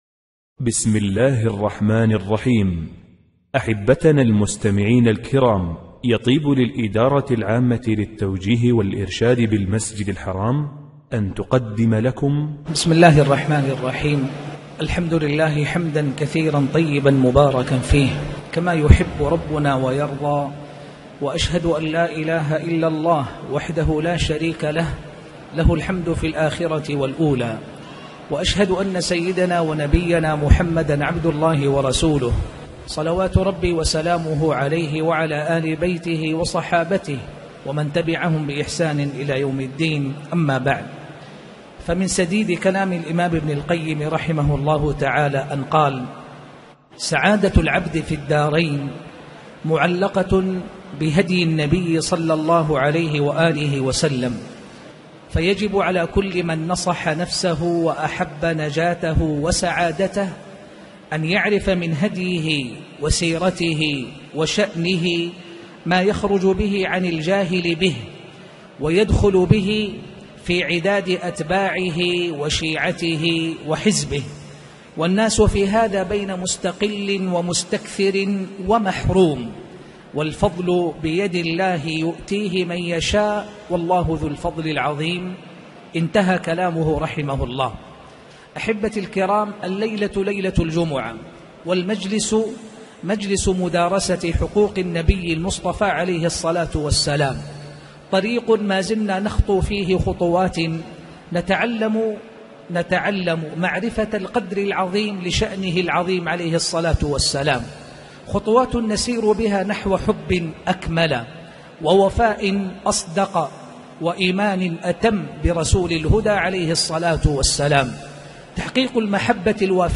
تاريخ النشر ٢٦ محرم ١٤٣٨ هـ المكان: المسجد الحرام الشيخ